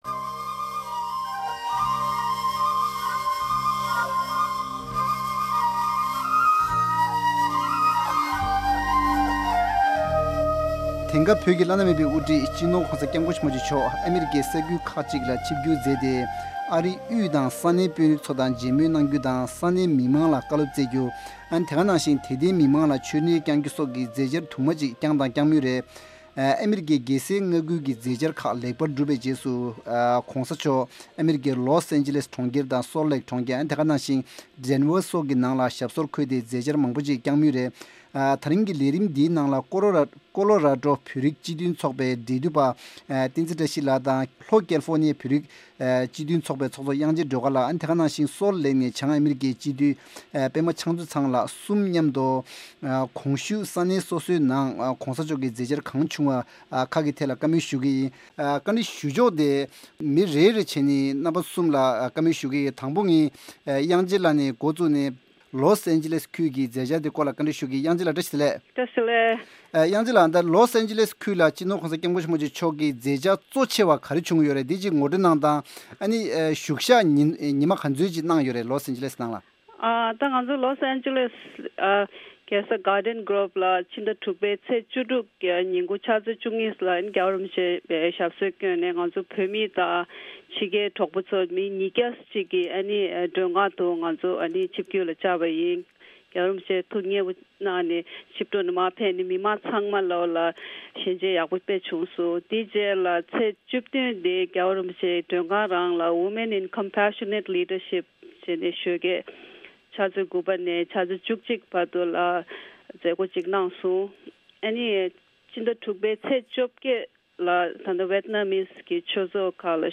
གཟའ་འཁོར་འདིའི་བགྲོ་གླེང་མདུན་ལྕོག་ལས་རིམ་ནང་སྤྱི་ནོར་༧གོང་ས་སྐྱབས་མགོན་ཆེན་པོ་མཆོག་གིས་ཨ་མི་རི་ཀའི་གྲོང་ཁྱེར་ལོས་ཨེན་ཇི་ལིས་དང། སོཏ་ལེག་། དེ་བཞིན། བྷོལ་ཊར་སོགས་ཀྱི་མཛད་འཆར་སྐོར་༧གོང་ས་མཆོག་གི་མཛད་འཆར་གོ་སྒྲིག་ཞུ་མཁན་གྲས་སུ་ཡོད་པའི་མི་སྣ་དང་ཚོགས་པ་གསུམ་གྱི་འབྲེལ་ཡོད་མི་སྣར་གནས་དྲི་ཞུས་པའི་ལས་རིམ་འདི་གསན་གྱི་རེད།